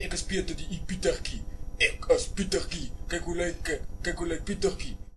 Tags: pimp my ride afrikaans dub